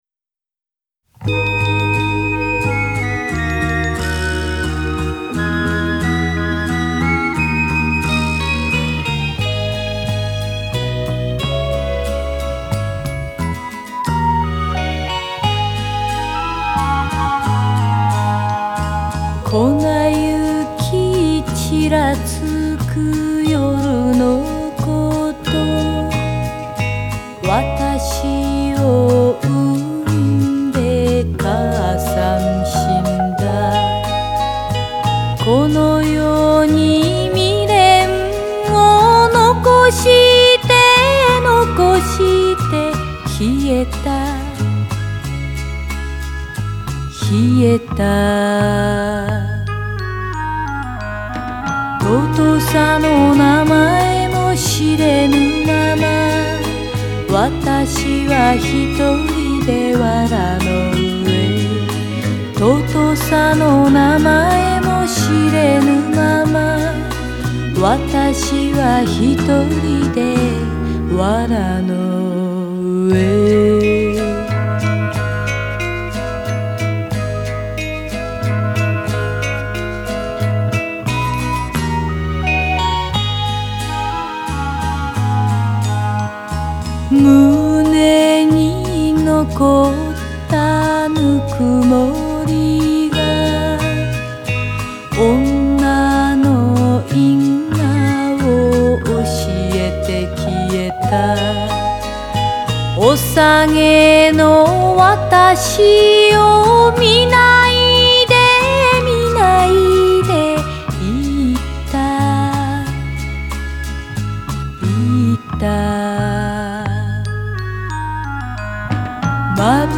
Жанр: Enka / J-pop